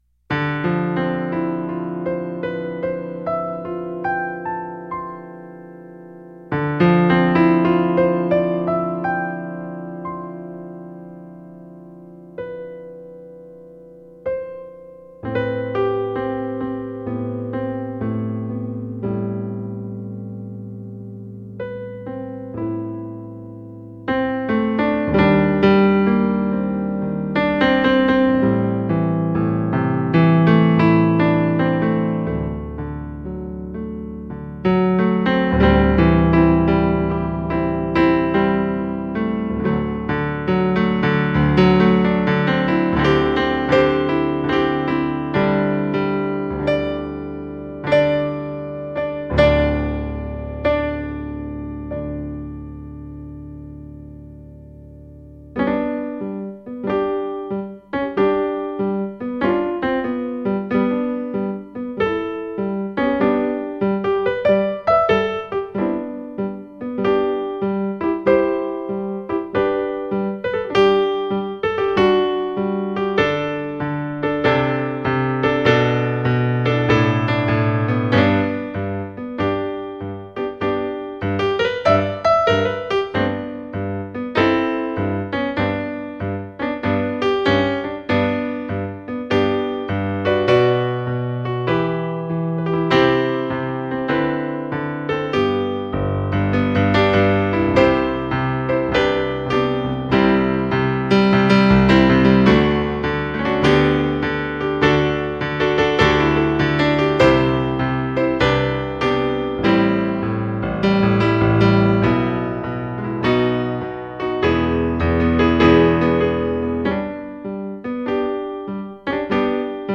Jazz Variation of Wagner's Bridal Chorus